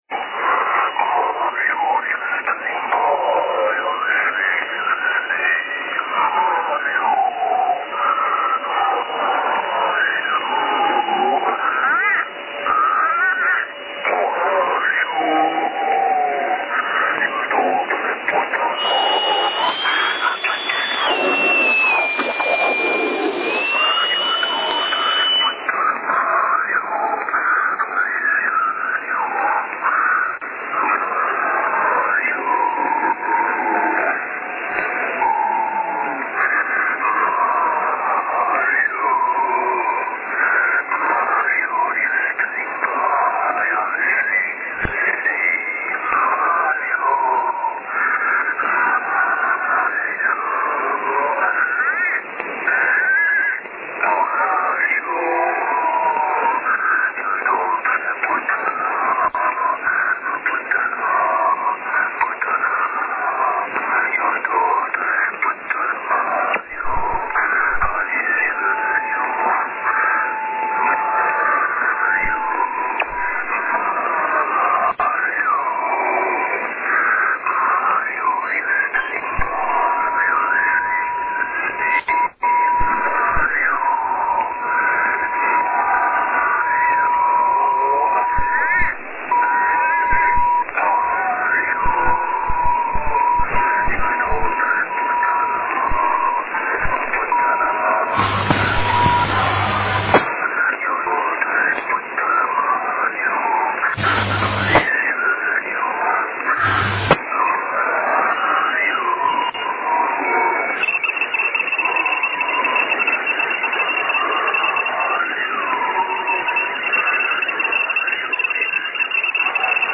Звуки говорящих
zvuki-govoriashchikh.mp3